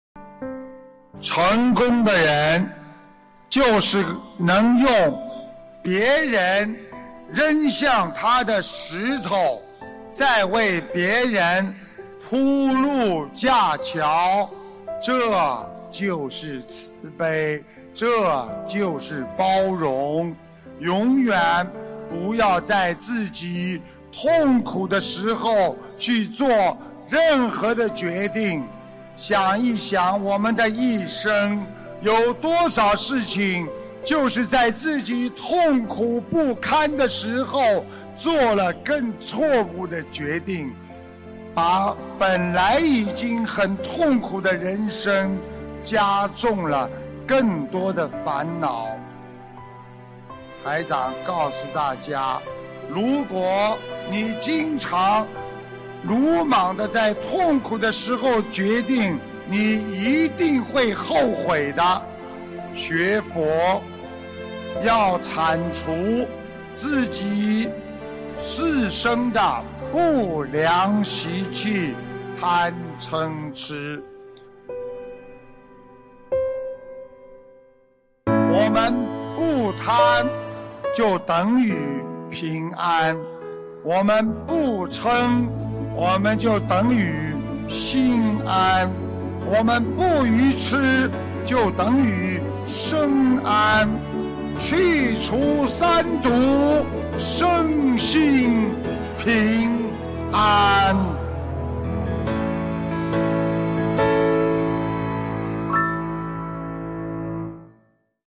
—— 2015年1月24日 马来西亚 槟城法会开示